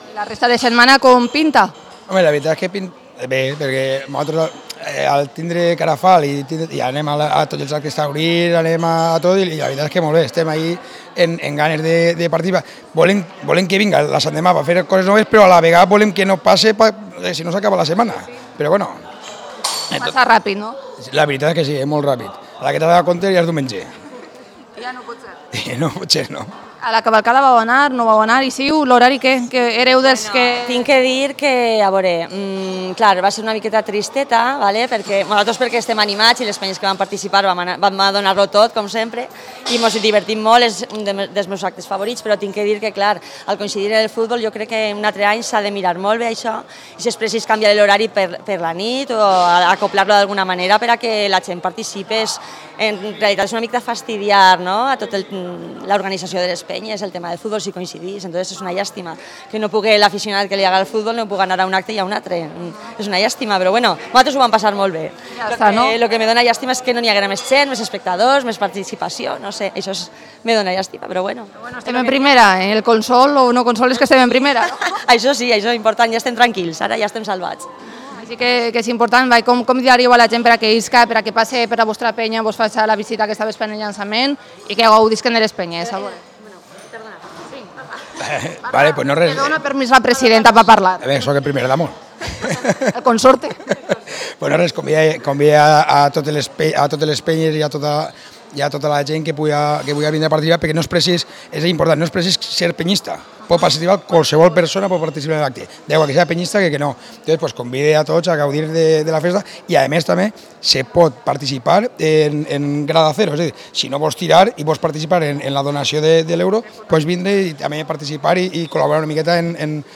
Esta semana recibimos a los protagonistas de la fiesta en una bodeguilla muy especial desde el restaurante El Casino.